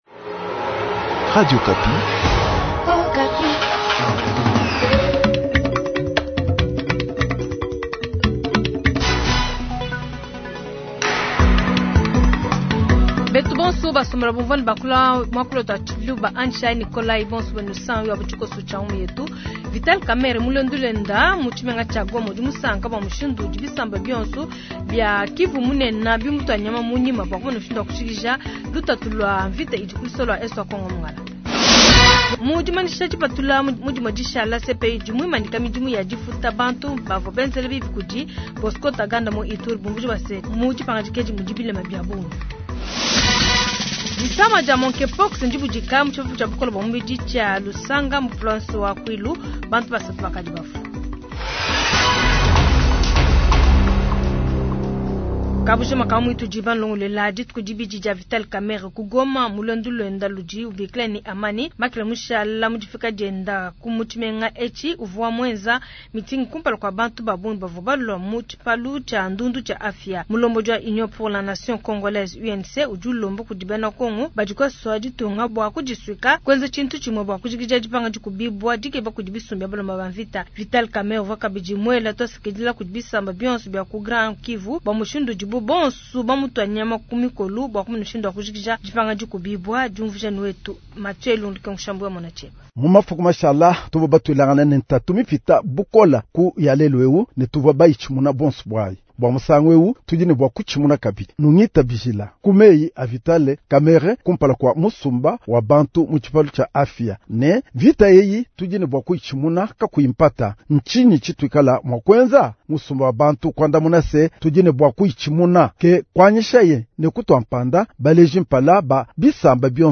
Journal soir
Goma : Séjour de VK à Goma, Reportage